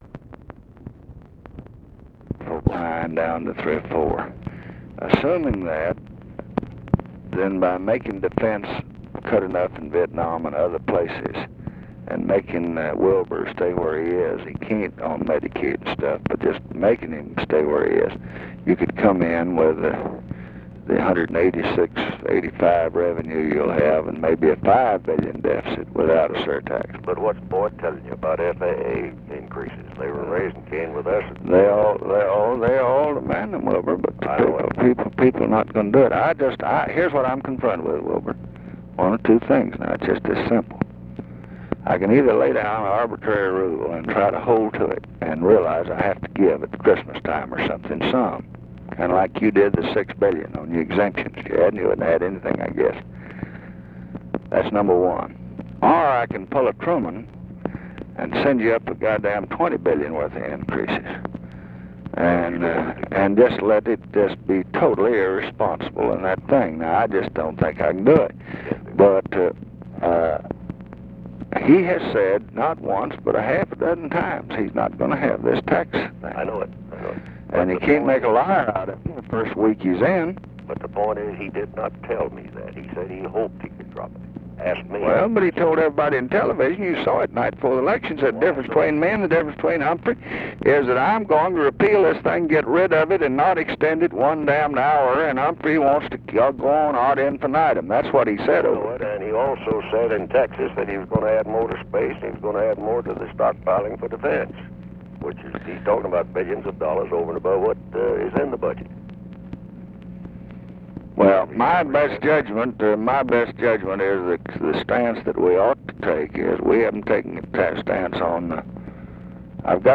Conversation with WILBUR MILLS, November 16, 1968
Secret White House Tapes